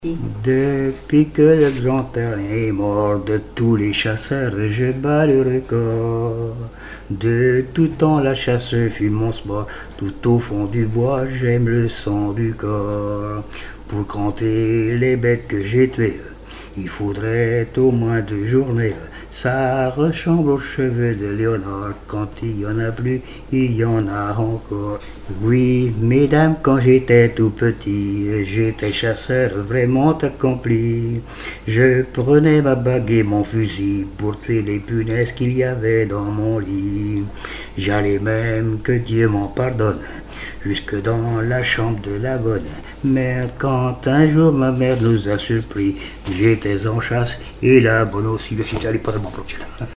Mémoires et Patrimoines vivants - RaddO est une base de données d'archives iconographiques et sonores.
chansons et témoignages
Pièce musicale inédite